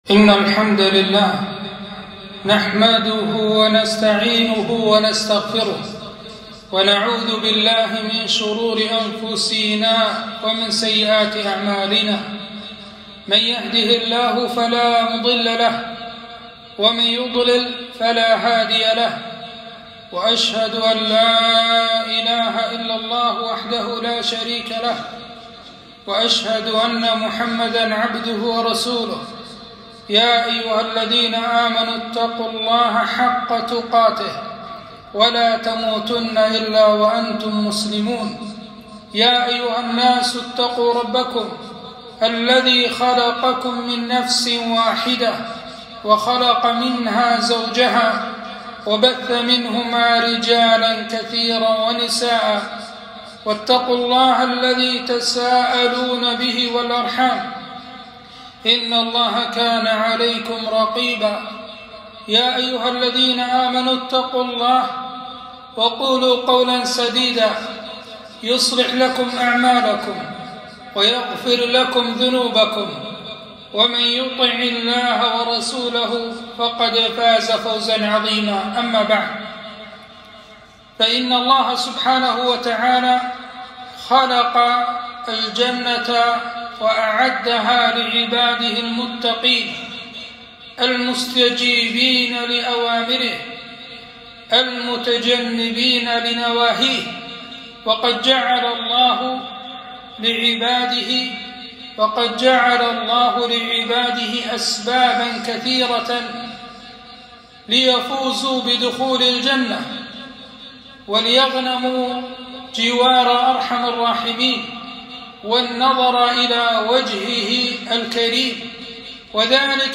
خطبة - أكثر ما يدخل الناس الجنة